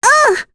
Reina-Vox_Damage_01.wav